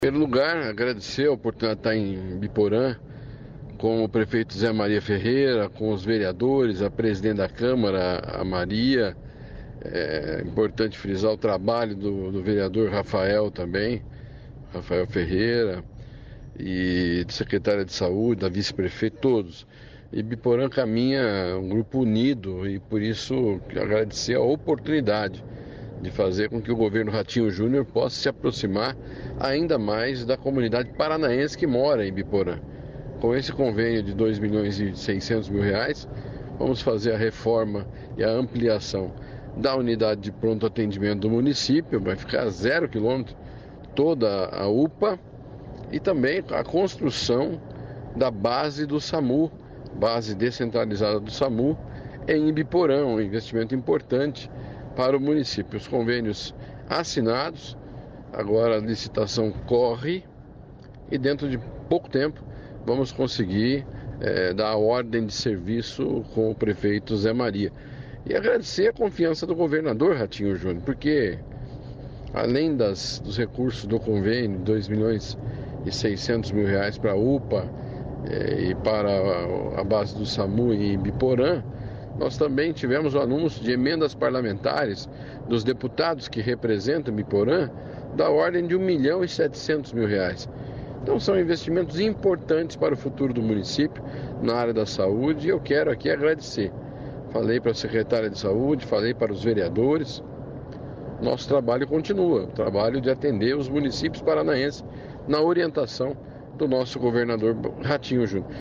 Sonora do secretário da Saúde, Beto Preto, sobre o investimento na saúde de Ibiporã